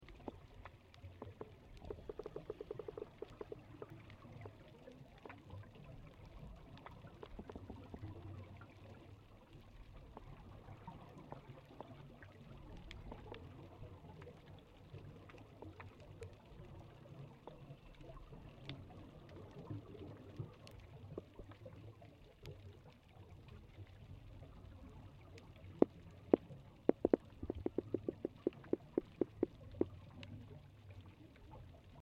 物種名稱 銀帶棘鱗魚 Sargocentron ittodai
錄音地點 台東縣 綠島鄉 中寮港
錄音環境 水下